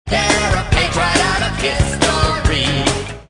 Flintstones_exclamation.mp3